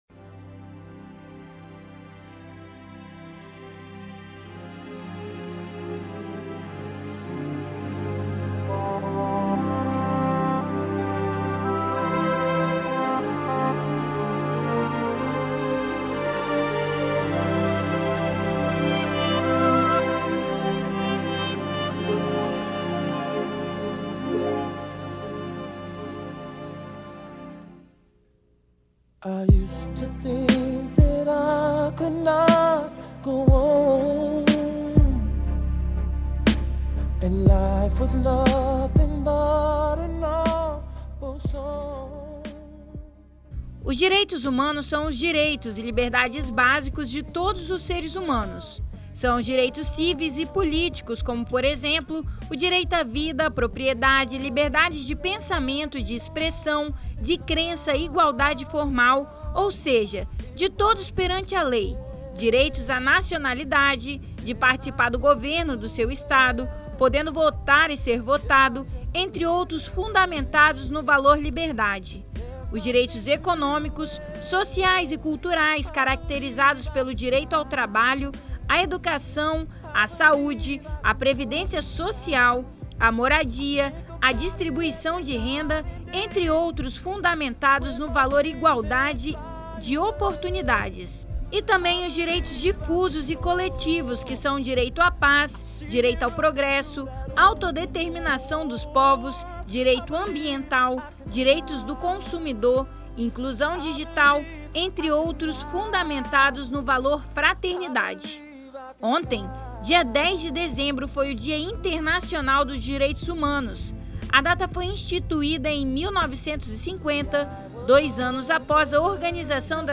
Confira a matéria e saiba mais sobre o assunto.